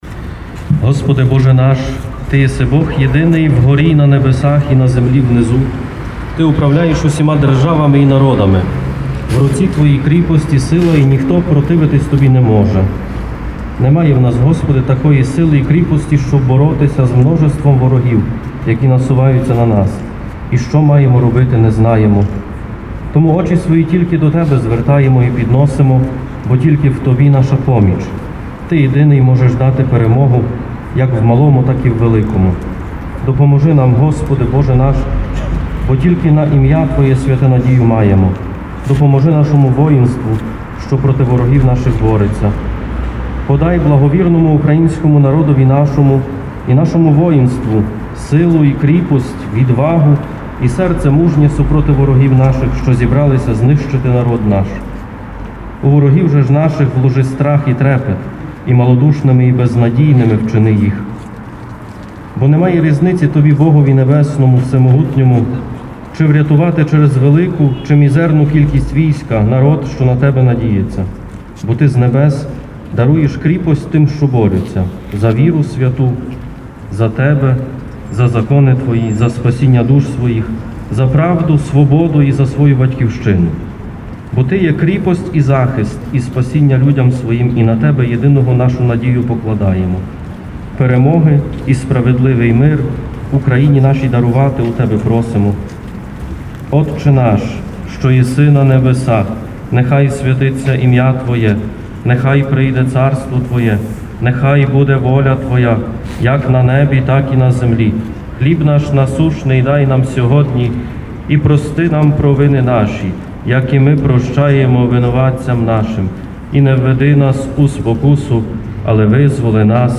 Bielszczanie spotkali się na pl. Chrobrego, aby pomodlić się za pokój w zaatakowanym kraju.